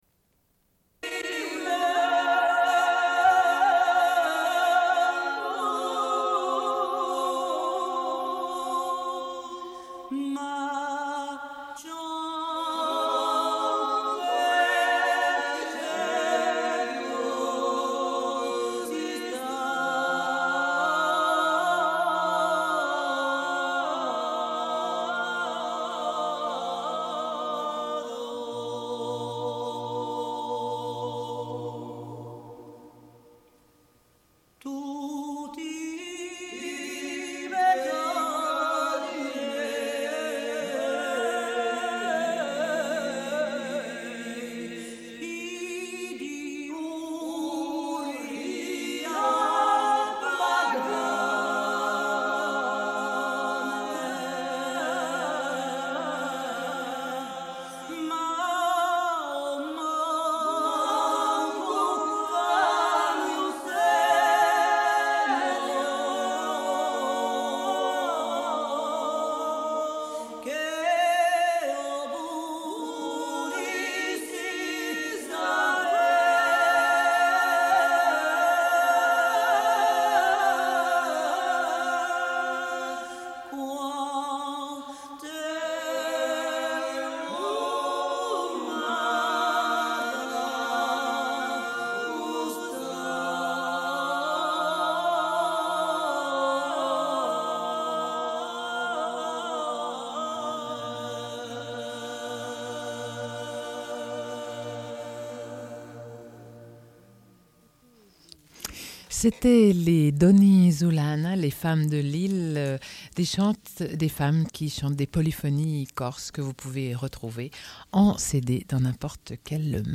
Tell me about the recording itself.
Une cassette audio, face B